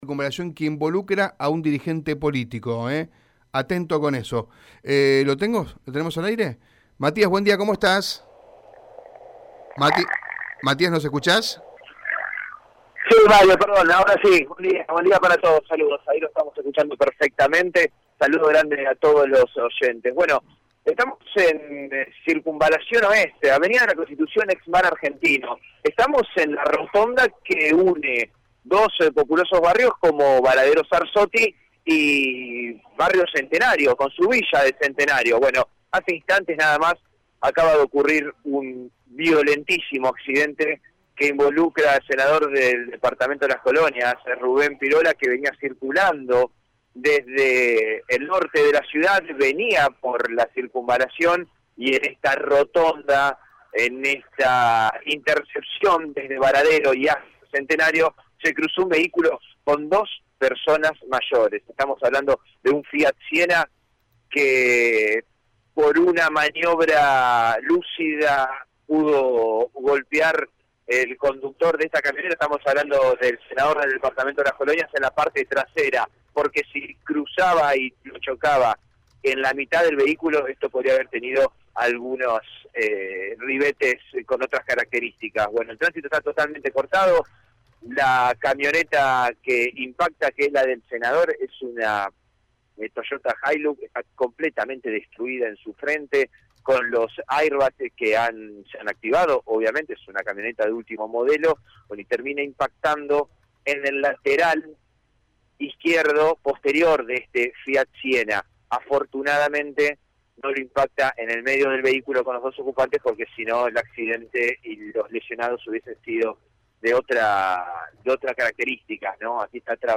Según el móvil de Radio EME, en el inicio de la Avenida «La Constitución Argentina», a la altura de la rotonda que une a los barrios Centenario y Varadero Sarosotti, se registró el impactante accidente entre la camioneta del legislador santafesino y un automóvil conducido por una pareja mayor.